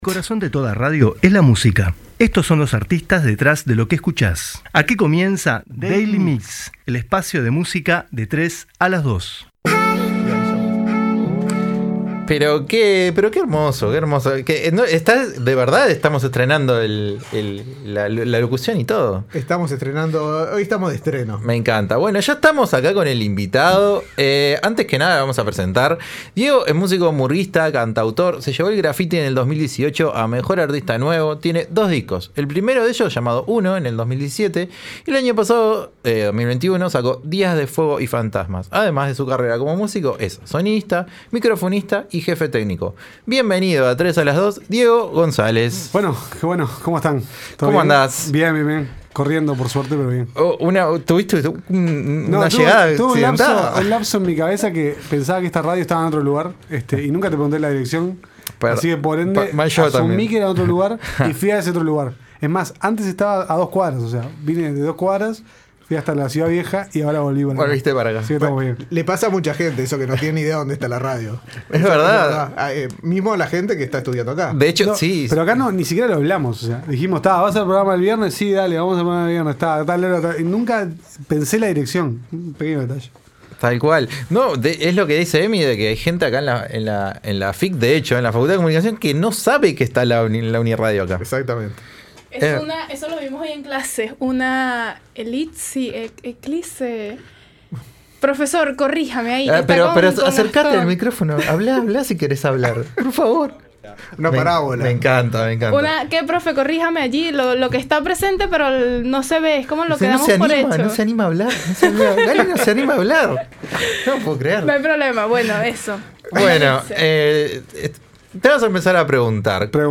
Entrevista
Música en vivo en el estudio de UNI Radio